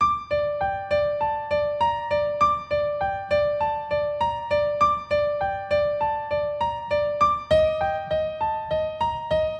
描述：电钢琴100bpm的Bbm节奏和弦
Tag: 和弦 钢琴